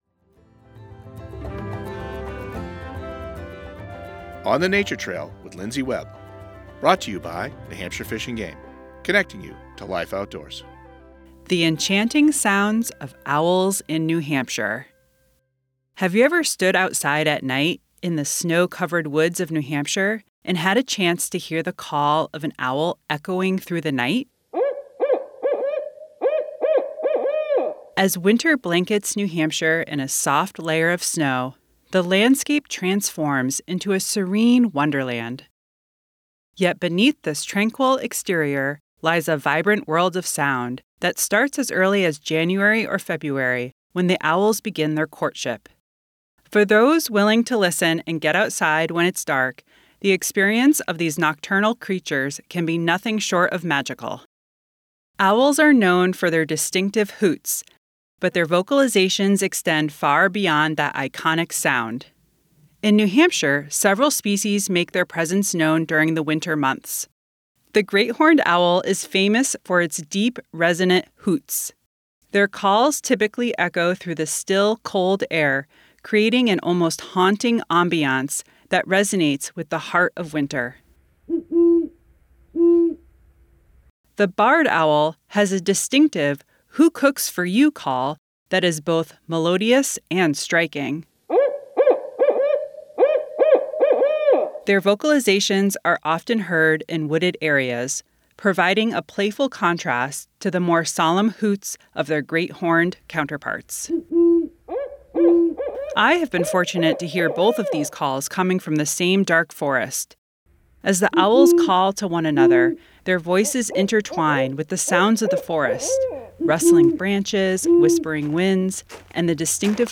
Winter Owls
In this episode of On the Nature Trail, we step into the quiet, snow-covered forests of New Hampshire at night to listen for the enchanting calls of owls. From the deep, resonant hoots of the Great Horned Owl to the playful “who cooks for you?” of the Barred Owl, these nocturnal voices fill the winter woods with life. We also explore the eerie trills of the Eastern Screech Owl and the persistent “toot-toot-toot” of the Northern Saw-whet Owl, all sounding off as the breeding season begins.
winter-owls.mp3